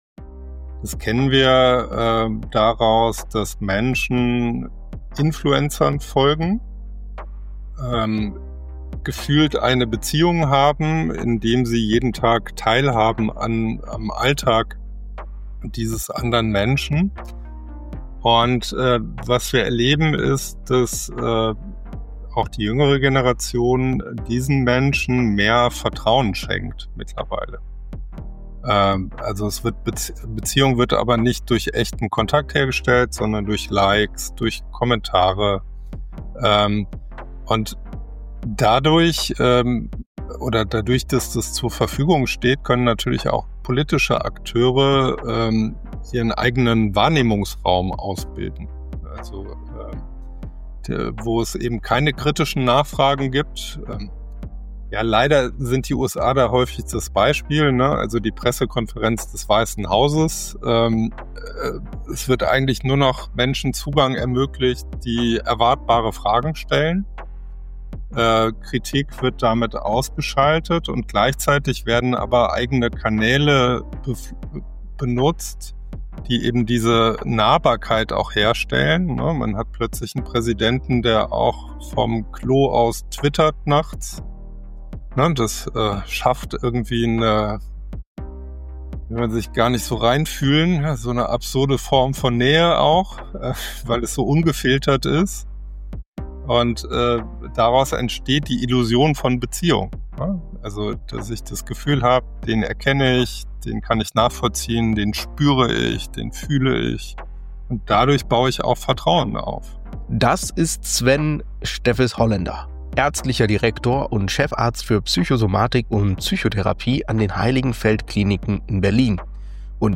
Wenn der Präsident vom Klo twittert: Ein Gespräch über Angst, Algorithmen und absurde Nähe Oder: Warum Social Media vielleicht das neue Fleischessen ist.